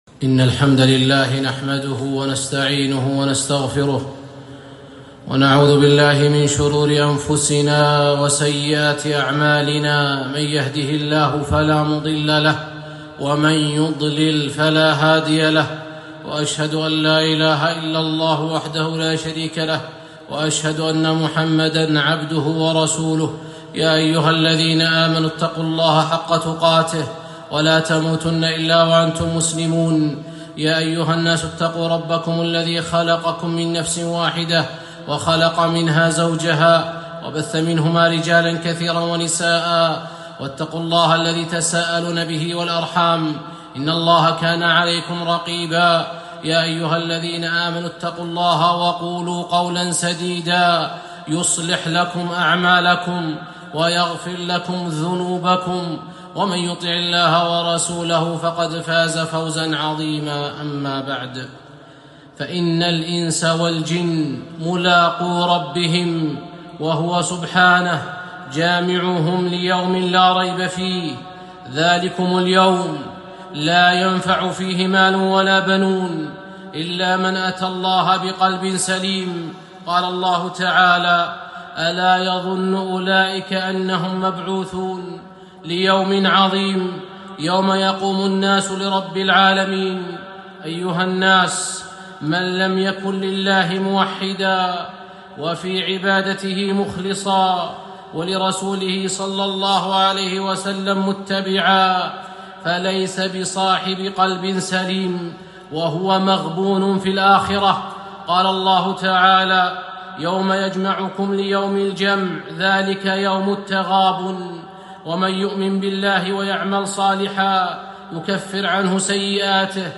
خطبة - حفظ القلب